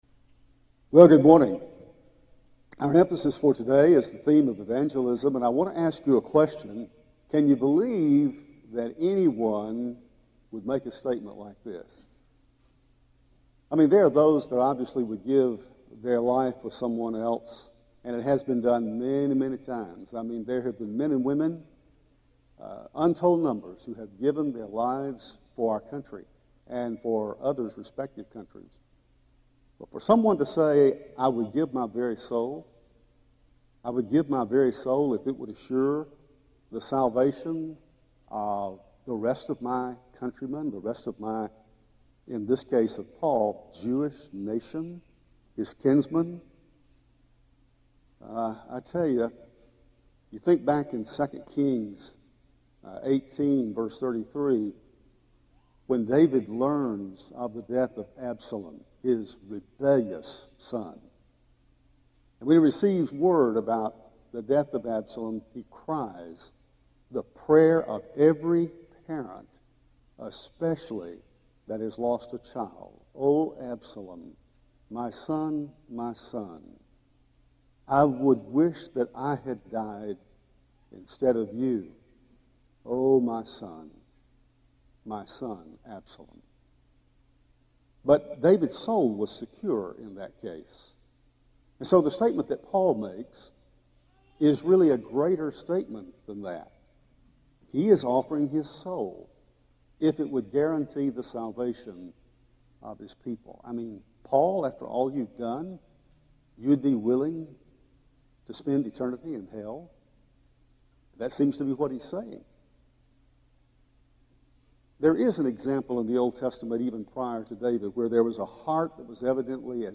Foundation Sunday